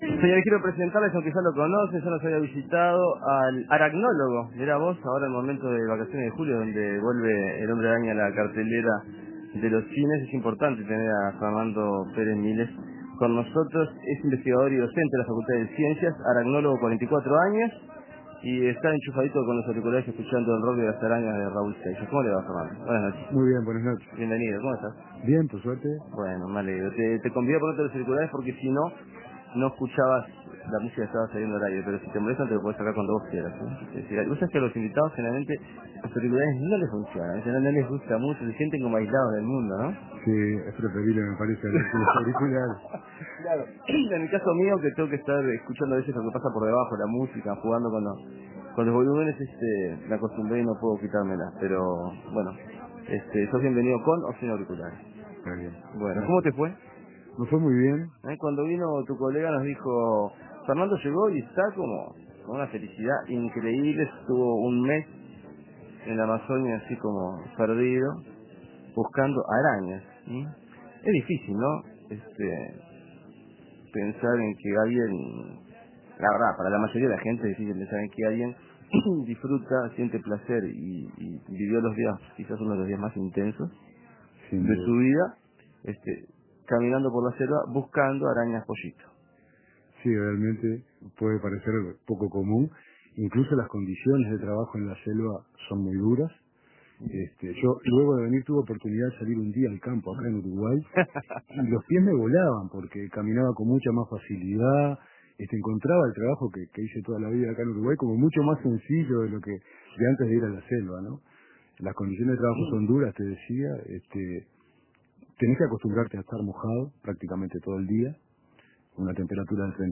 Planetario Todo sobre las arañas y algunas novedades desde el Amazonas. Entrevista